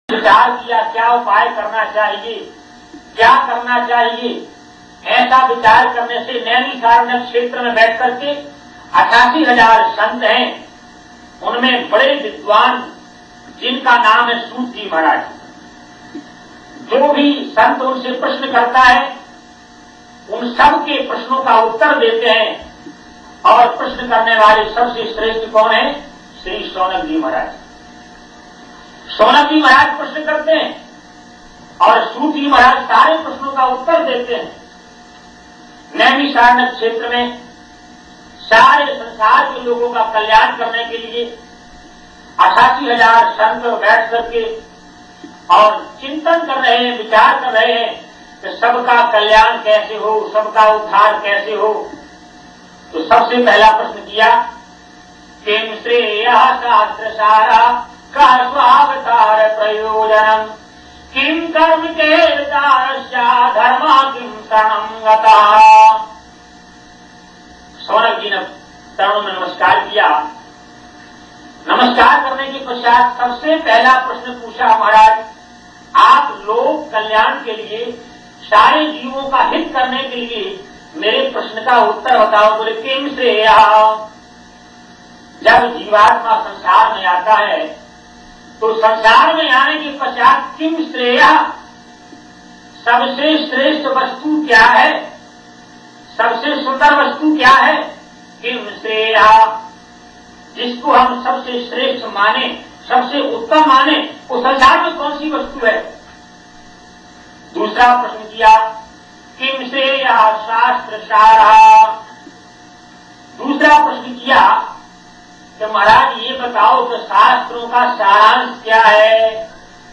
The following were recorded live at a temple in New York: